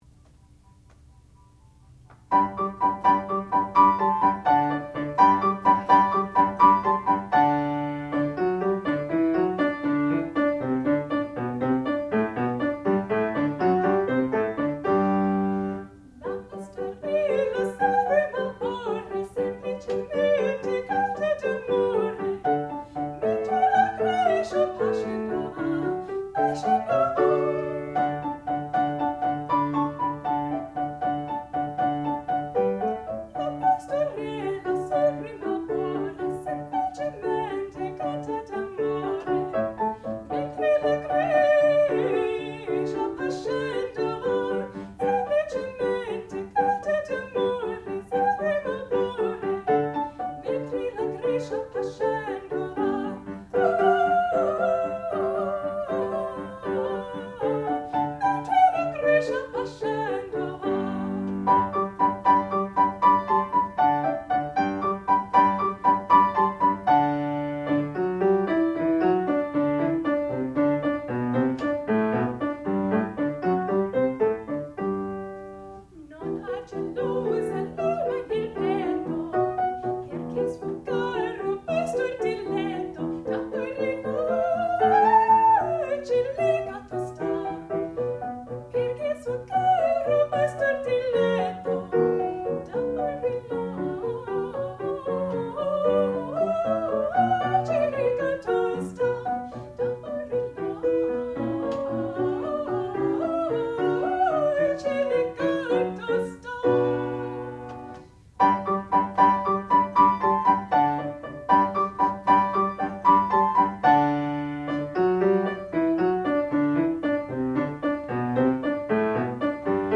sound recording-musical
classical music
piano
Student Recital
soprano